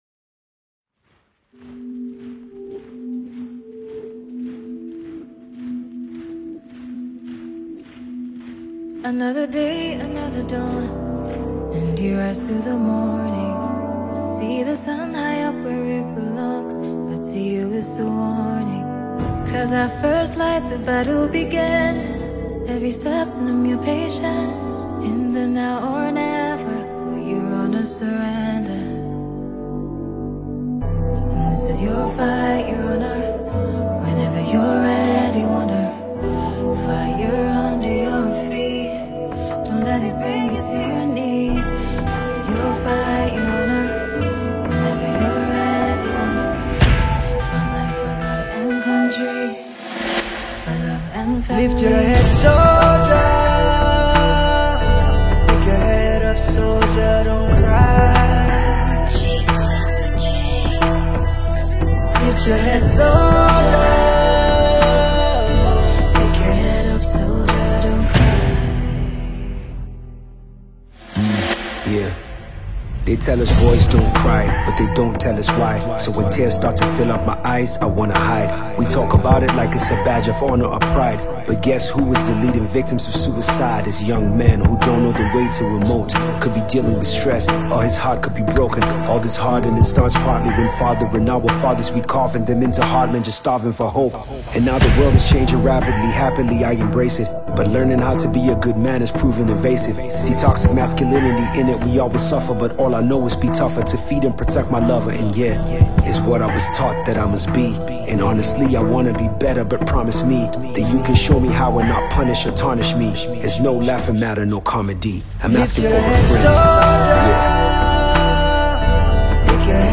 a multi-skilled Nigerian rapper
a Nigerian female vocalist musician